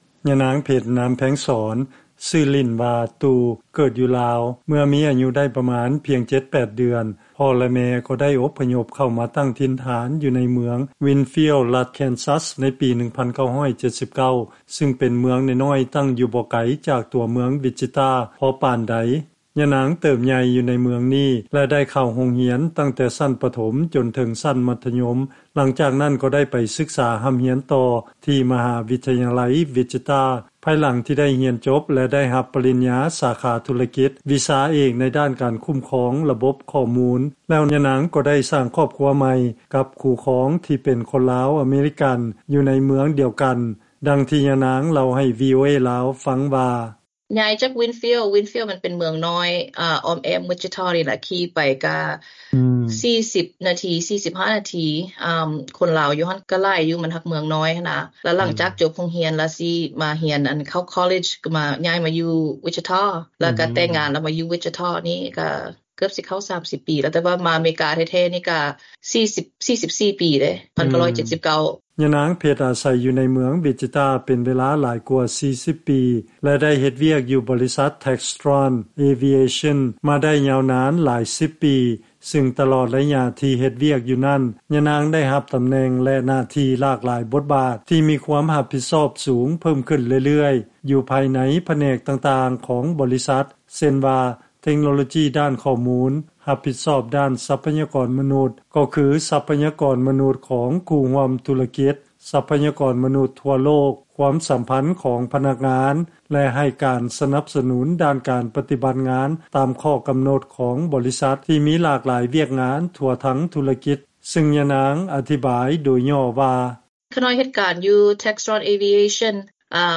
ລາຍງານ